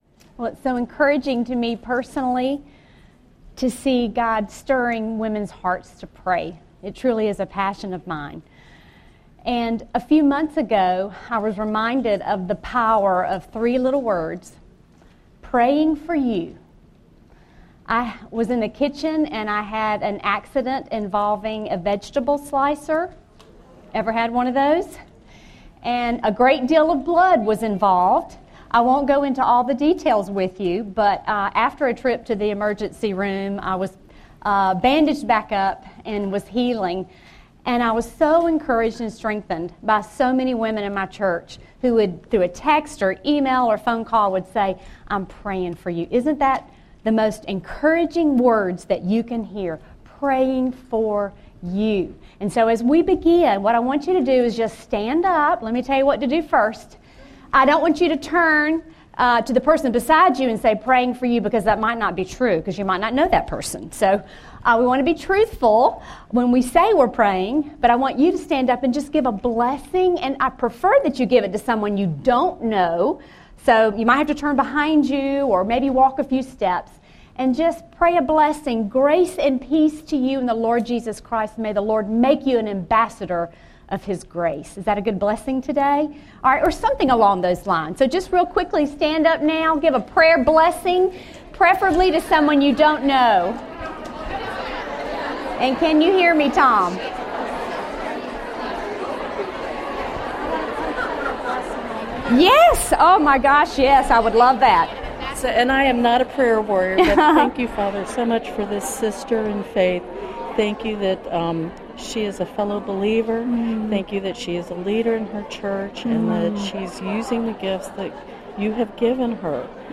Prayer: Moving Ministry from Powerless to Powerful | Revive '13 | Events | Revive Our Hearts